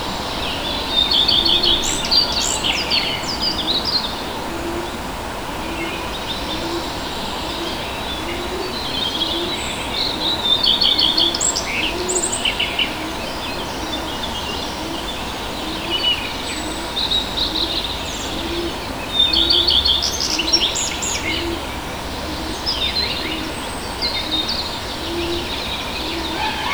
• early mountain birds habitat 8.wav
Great place to record the natural habitat of birds and animals in the beautiful Southern Carpathian Mountains. Recorded with Tascam DR 40